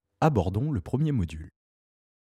KGbajb8iPNM_Page-de-garde-bip.wav